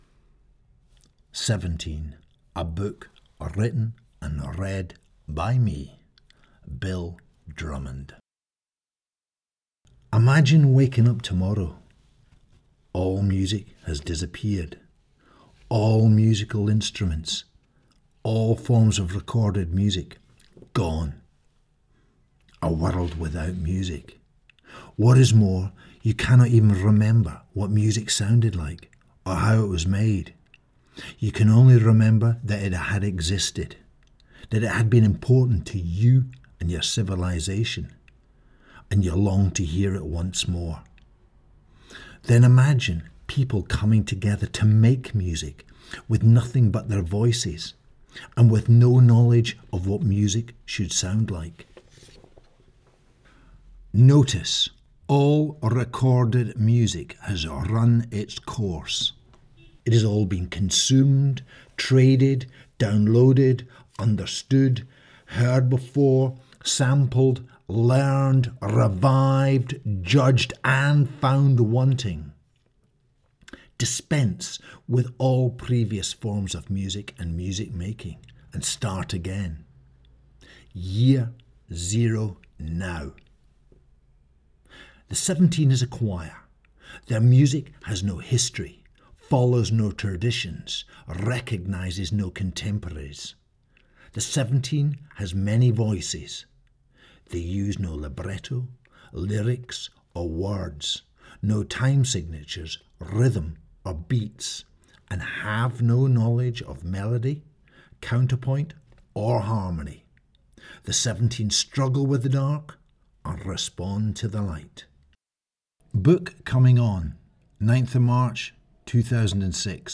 chapter one read by bill drummond